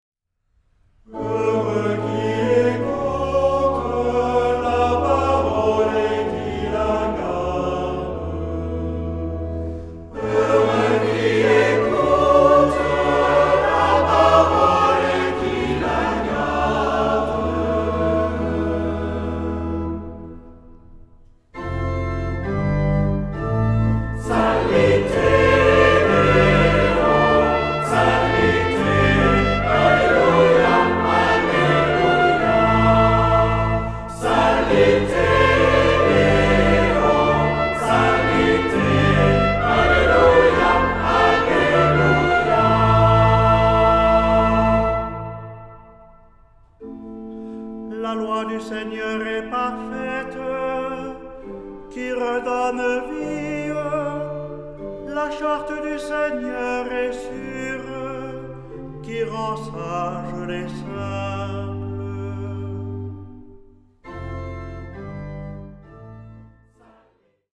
Genre-Style-Form: Sacred ; troparium ; Psalm
Mood of the piece: collected
Type of Choir: SATB  (4 mixed voices )
Instruments: Organ (1)
Tonality: A major